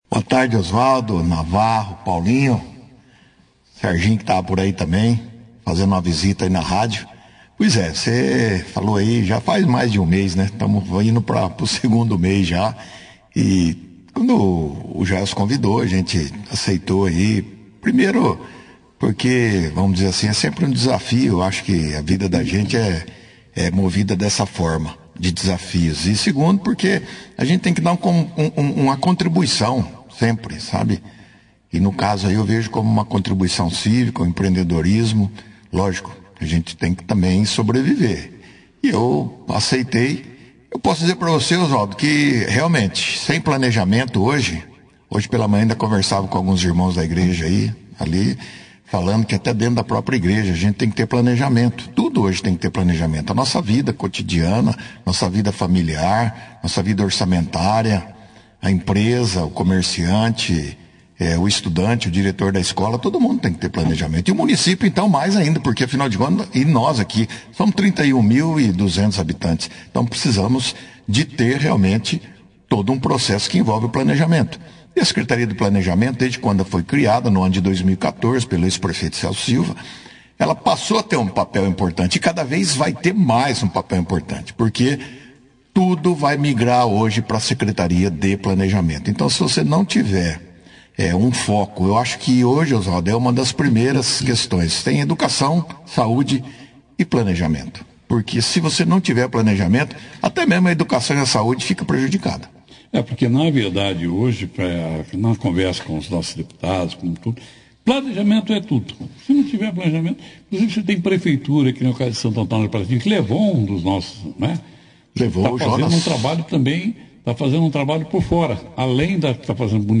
O secretário de planejamento de Bandeirantes, Wanderson de Oliveira, (foto), foi destaque na edição deste sábado, 15/07, do jornal Operação Cidade falando dos projetos em andamento em sua secretaria e a necessidade de planejamento para obter recursos para obras através de convênios, tanto em âmbito estadual quanto federal.